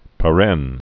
(pə-rĕn)